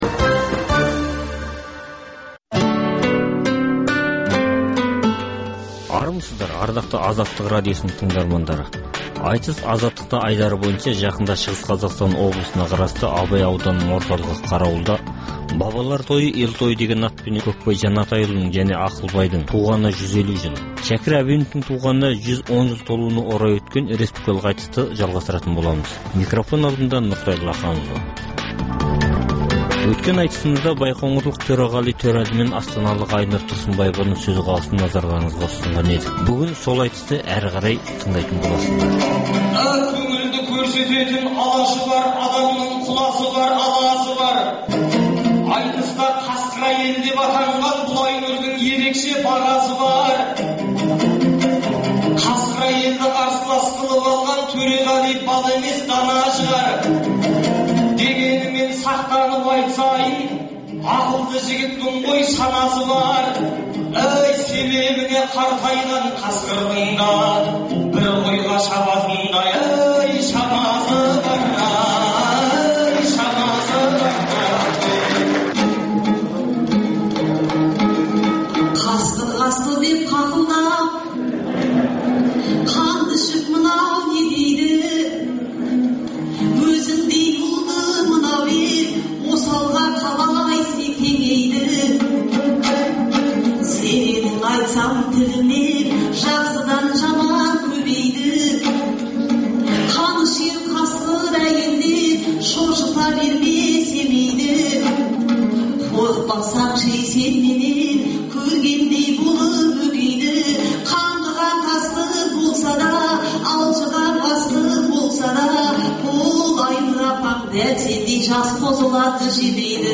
Дөңгелек үстел – Саяси және әлеуметтік саладағы күннің өзекті деген күйіп тұрған тақырыптарын қамту үшін саясаткерлермен, мамандармен, Қазақстаннан тыс жердегі сарапшылармен өткізілетін талқылау, талдау сұхбаты